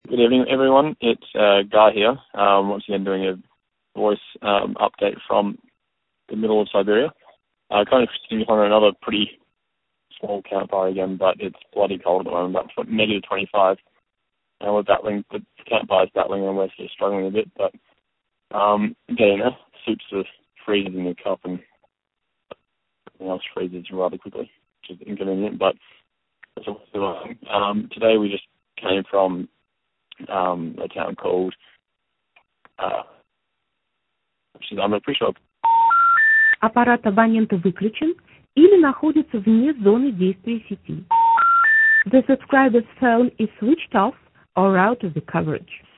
The wonderful noise you can hear is our motorbike, minus the exhaust muffler it abruptly decided it no longer required about 1km prior.
The footage I have is unbelievably shaky, because I shot it handheld from a motorbike sidecar doing 60km/hr down a bumpy road with no suspension (we hit a few potholes early on, whoops).